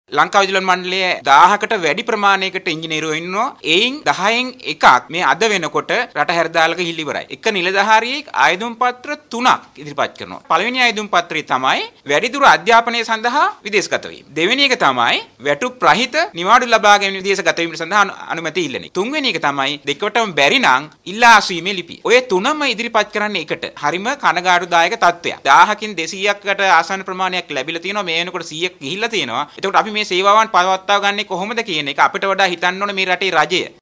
යෝජිත අසාධාරණ බදු සංශෝධනයට එරෙහිව වෘත්තීයවේදී සංගම් කැඳවු මාධ්‍ය හමුවකට එක්වෙමින් ඔවුන් සඳහන් කළේ වෘත්තීයවේදීන් රට හැරයෑමේ සීඝ්‍ර ප්‍රවණතාව හමුවේ අර්බුද රැසක් ඉදිරියේදී ඇතිවිය හැකි බවයි.